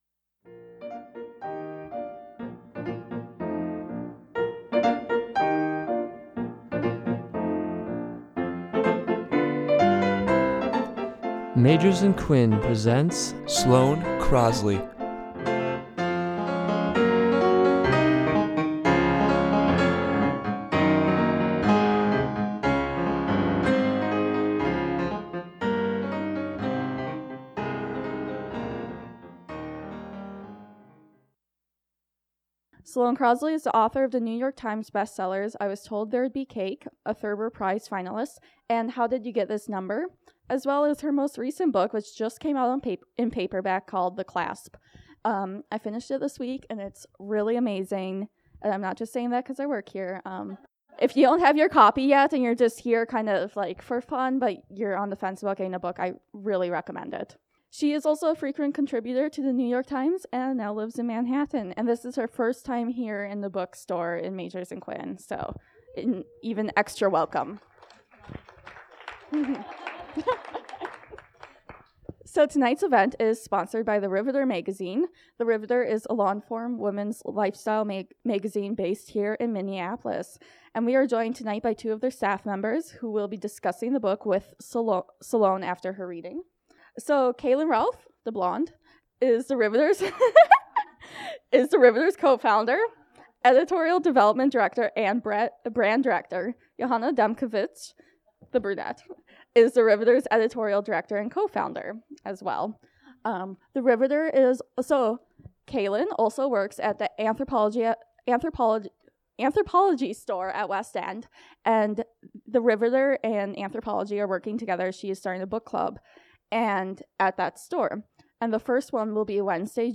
Sloane Crosley reads from her novel The Clasp, including a discussion with The Riveter Magazine.
We were so lucky to have Sloane Crosley visit the store in June.
Recorded at Magers and Quinn Booksellers on June 12, 2016.